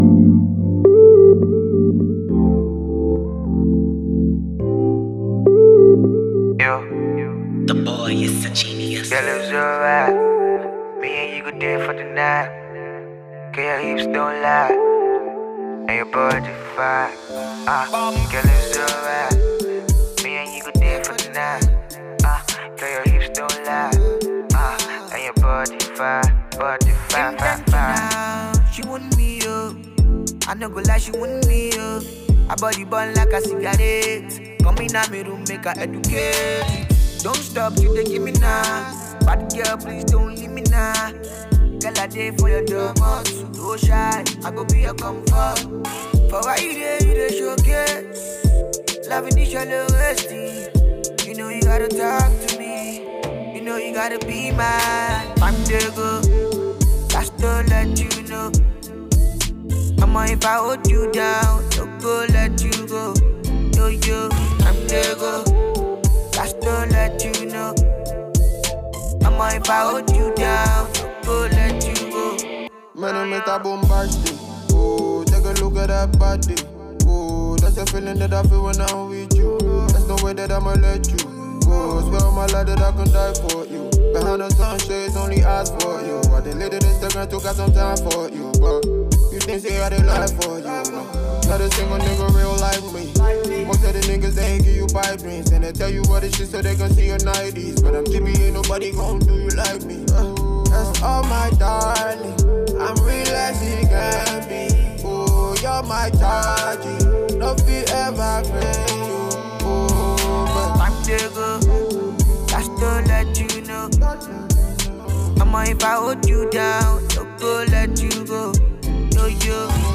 banging single